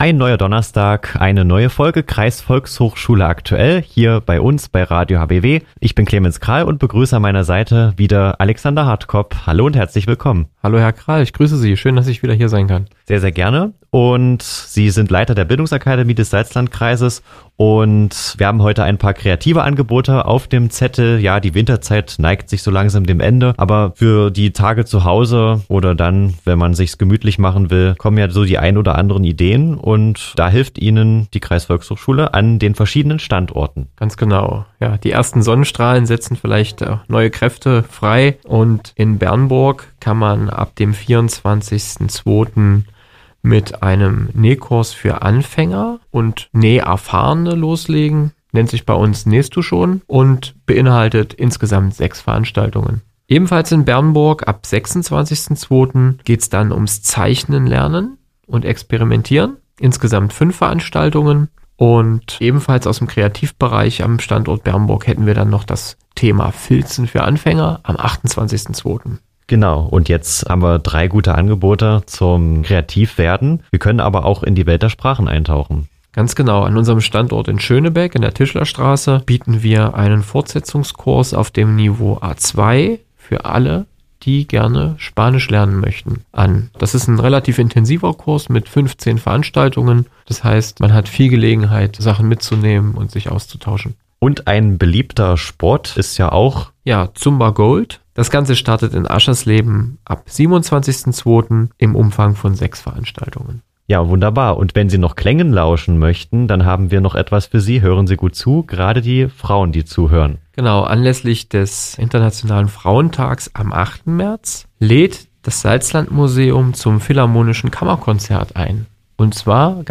Hörbeitrag vom 20. Februar 2025